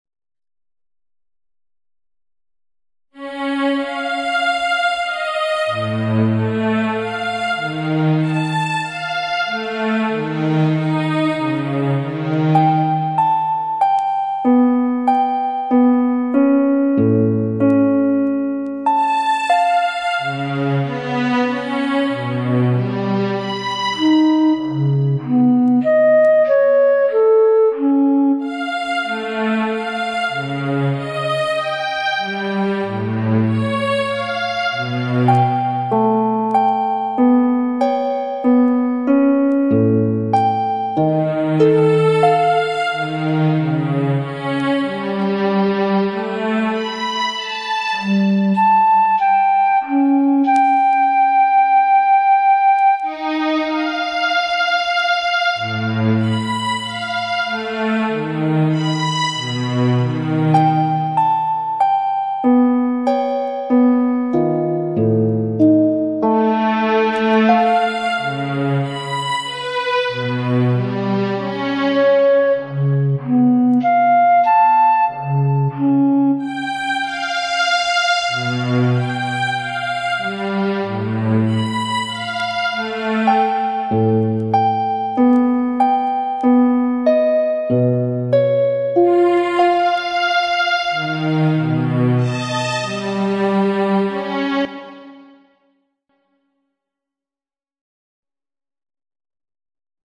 The sequence includes the four calcium sites and the three sequences that link them, and will play in unison except at points where there are different amino acids in the sequence of the two species.